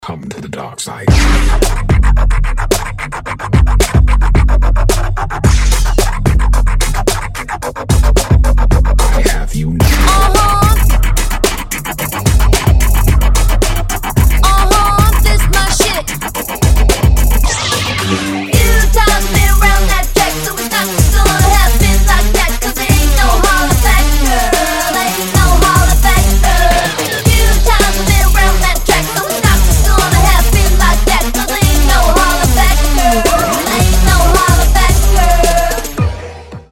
• Качество: 320, Stereo
dance
Club House
танцевальные